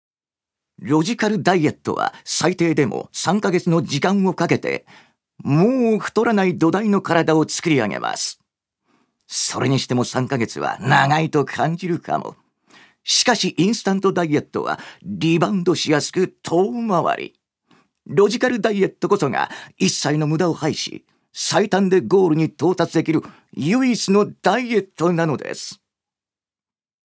預かり：男性
ナレーション２